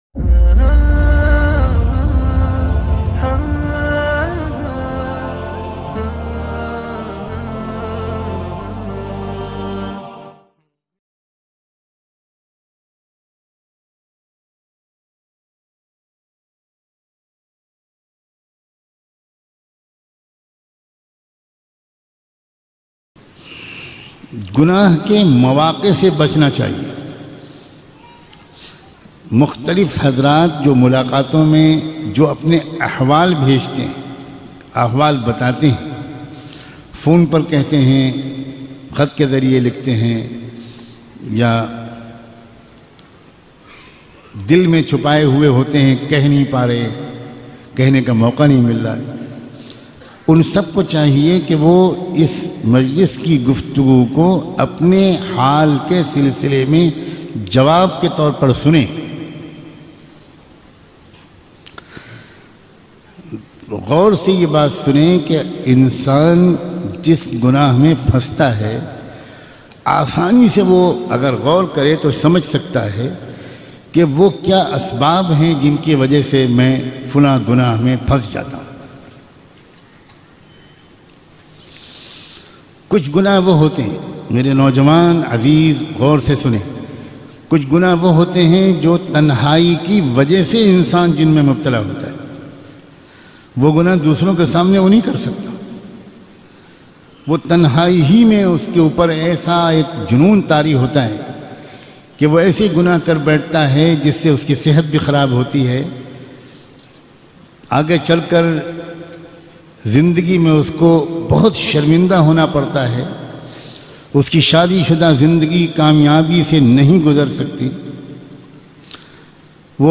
Akele Main Gunahon Se kaise bachen bayan mp3